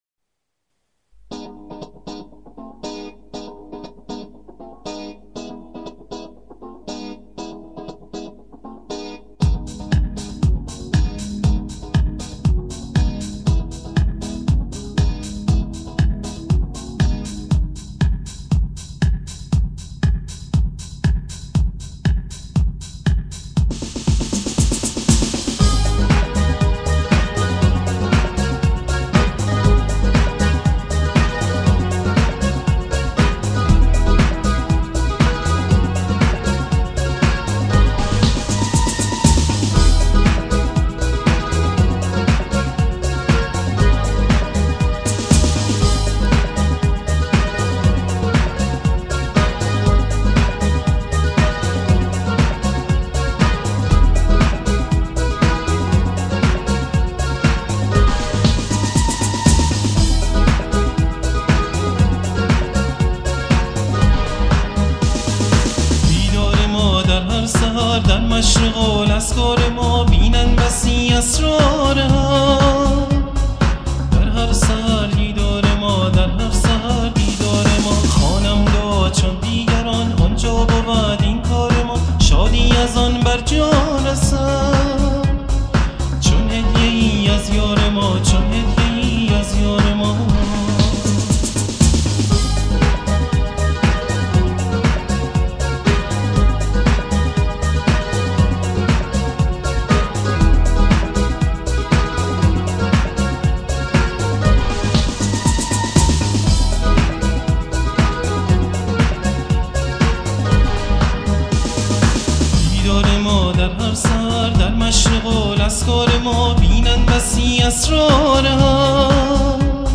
سرود - شماره 10 | تعالیم و عقاید آئین بهائی